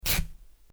Desgarre de tela
tela desgarrar desgarro rajar
Sonidos: Hogar